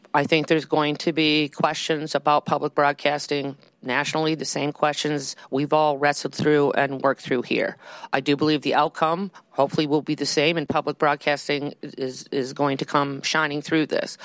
They packed a hearing room to make their case to legislators to reject budget cuts proposed by former Governor Kristi Noem.